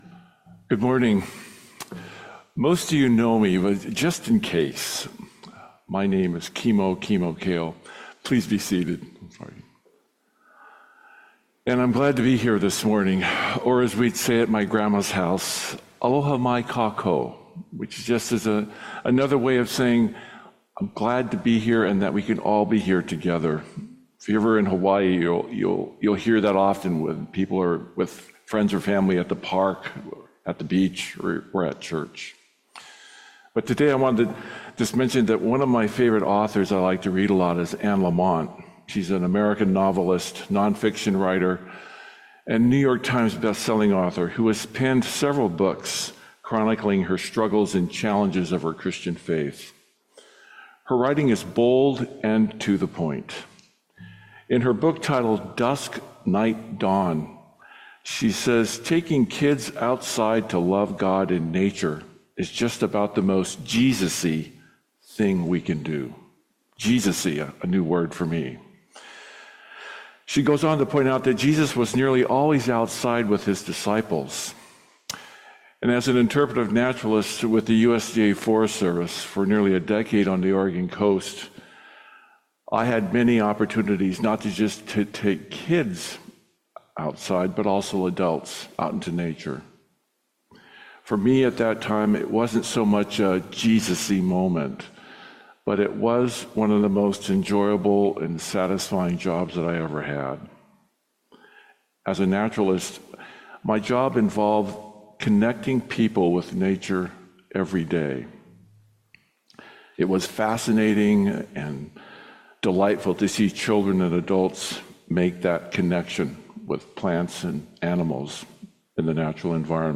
Sermon on October 5, 2025